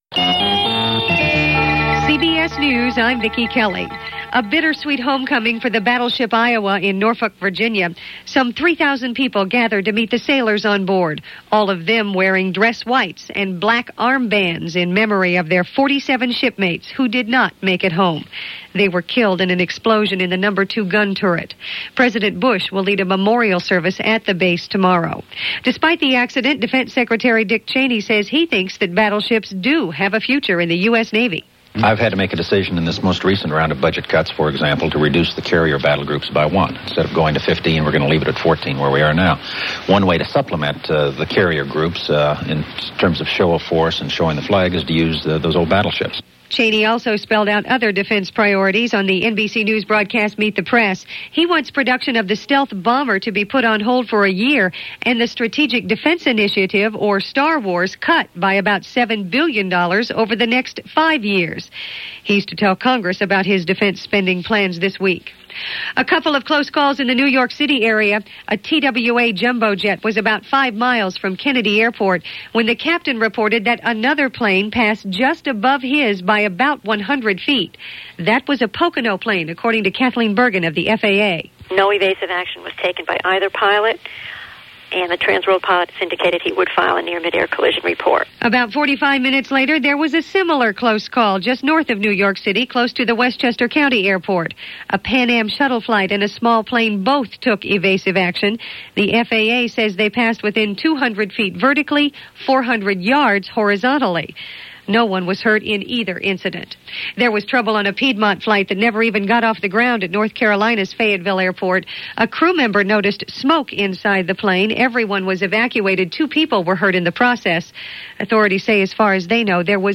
April 23, 1989 – CBS Radio Hourly News – Gordon Skene Sound Collection –